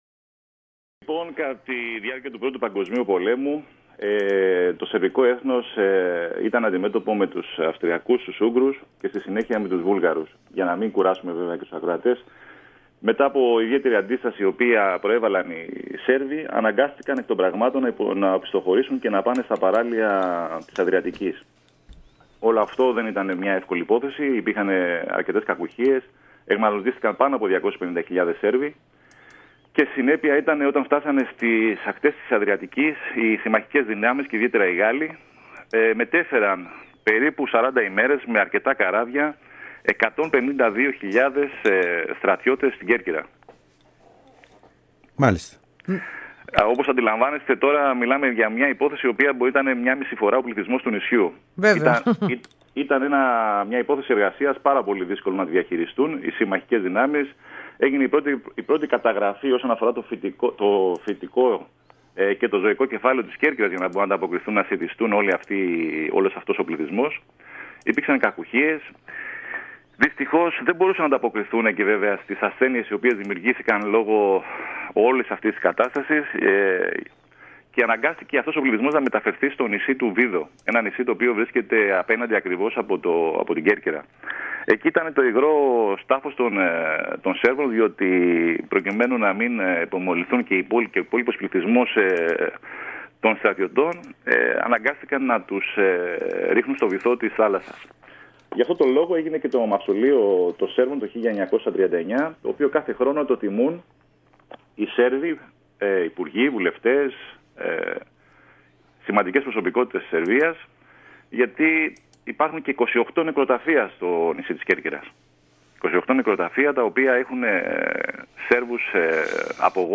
μίλησε στην ΕΡΑ ΣΠΟΡ